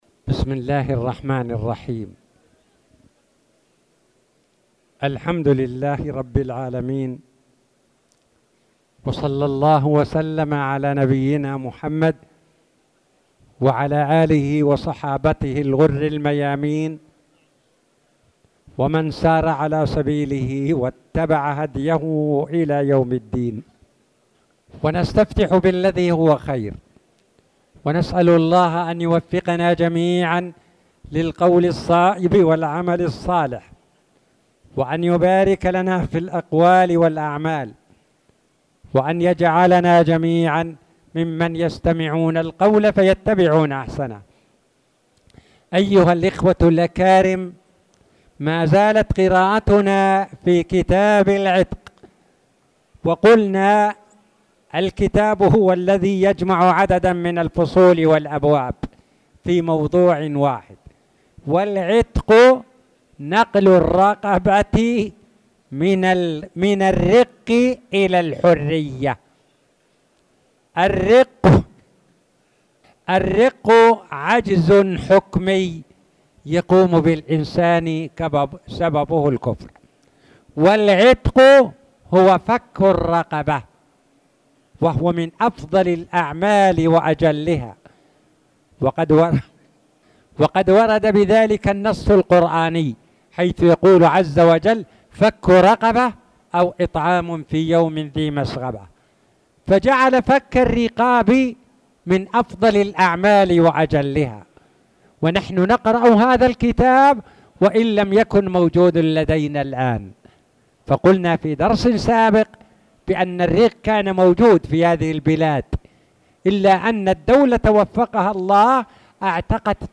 تاريخ النشر ١ محرم ١٤٣٨ هـ المكان: المسجد الحرام الشيخ